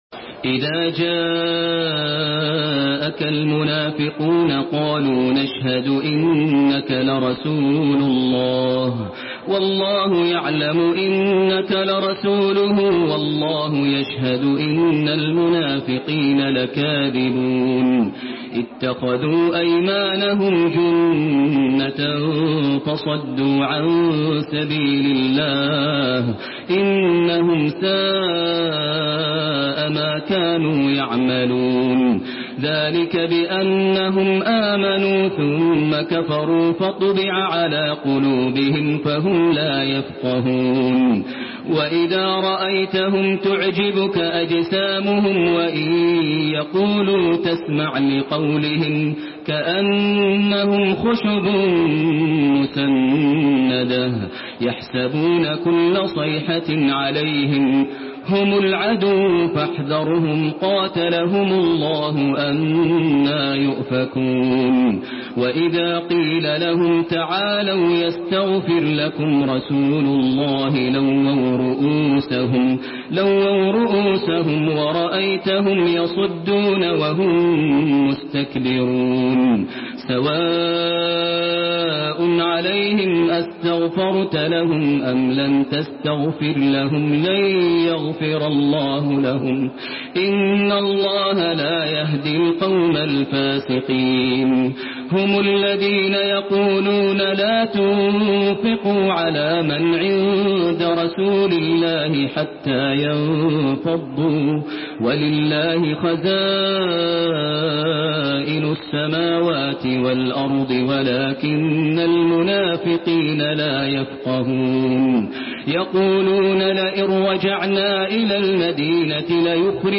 تحميل سورة المنافقون بصوت تراويح الحرم المكي 1432
مرتل حفص عن عاصم